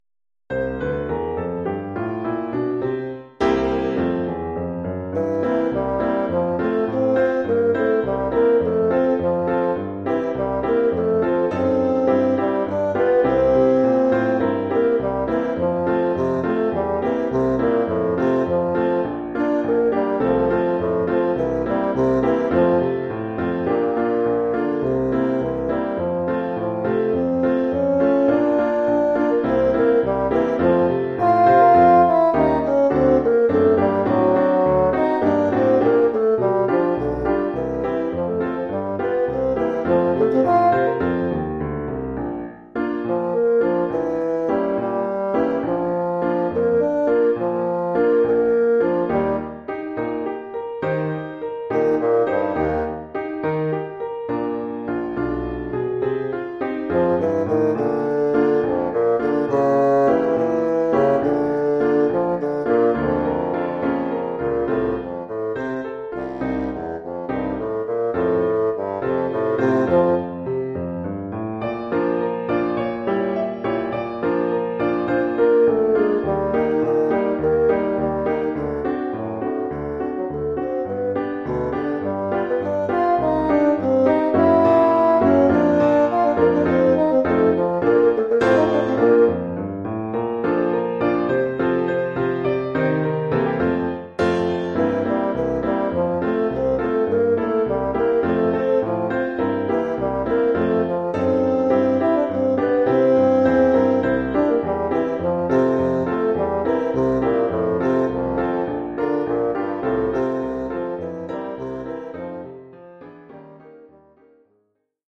1 titre, basson et piano : conducteur et partie de basson
Oeuvre pour basson et piano.